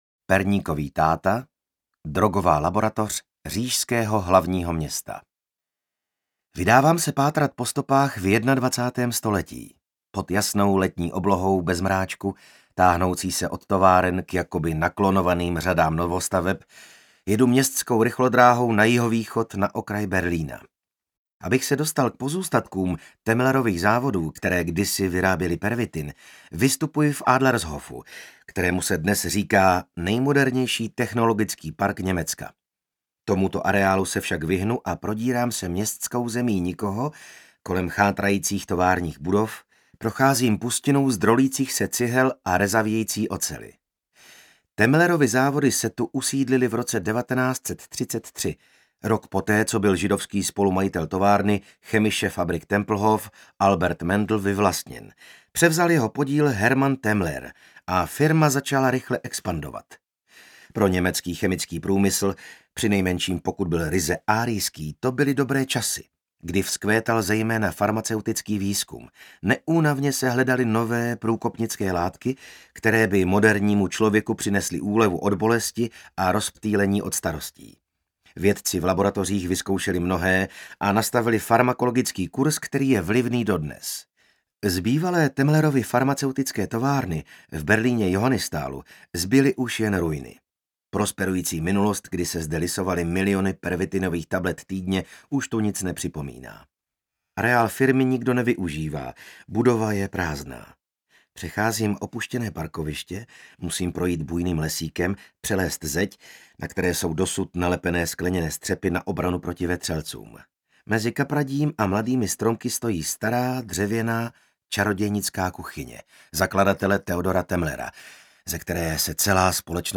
Totální rauš audiokniha
Ukázka z knihy
totalni-raus-audiokniha